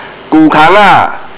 意指長滿野草的沼澤 : 萬里的龜吼 是唸 ㄍㄨ ㄎㄤ ku1-khang1